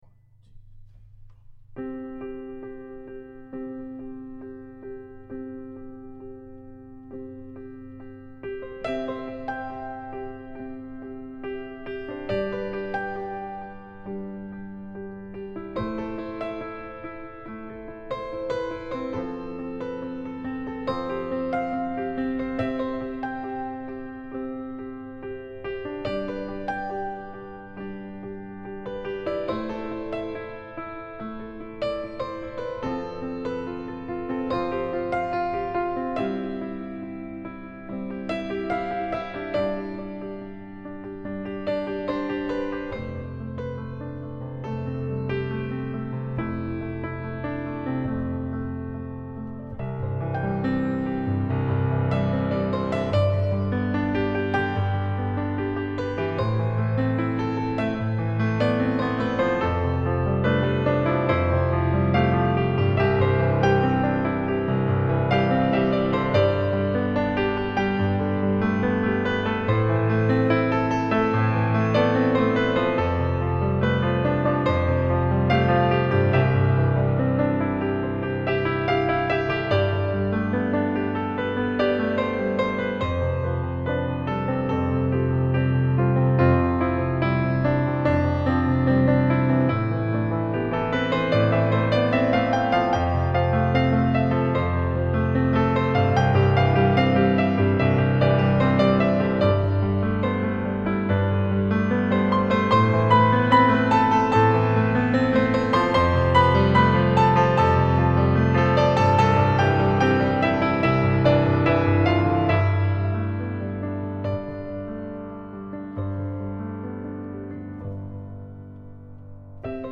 موسیقی کنار تو
الهام‌بخش , پیانو , عصر جدید , موسیقی بی کلام